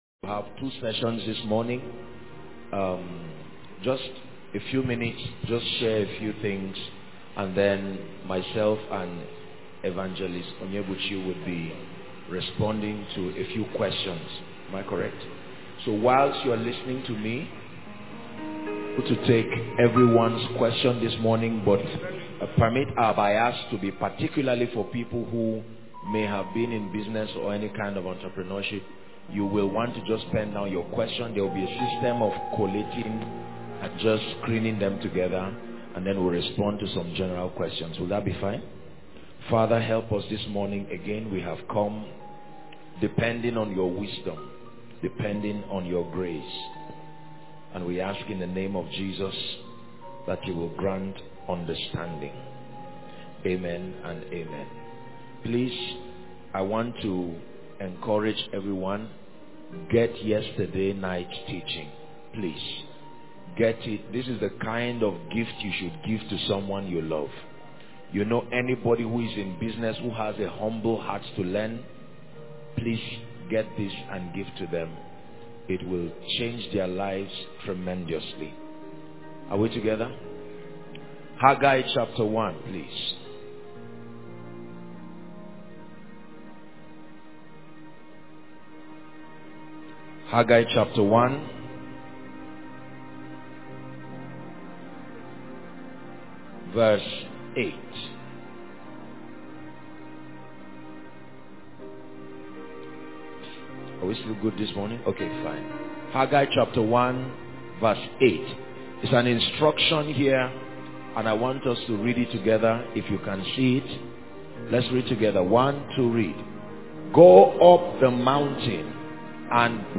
In this sermon